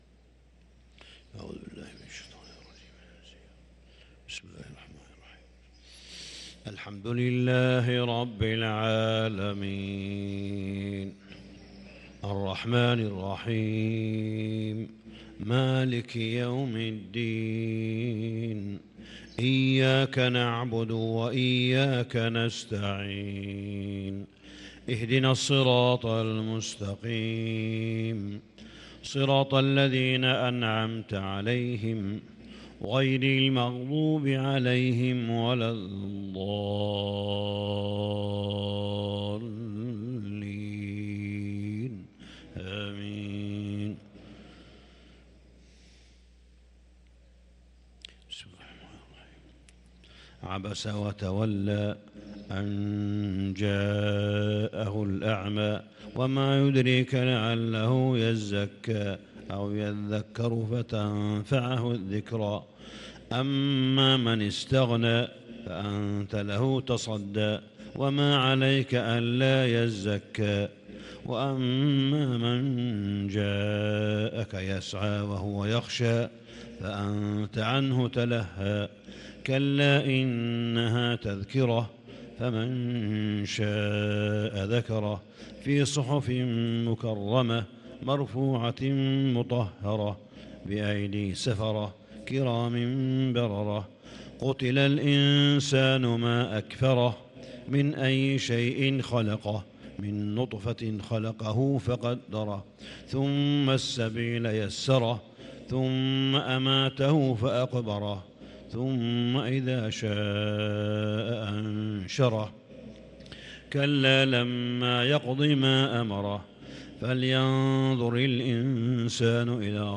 صلاة الفجر للقارئ صالح بن حميد 20 رمضان 1443 هـ
تِلَاوَات الْحَرَمَيْن .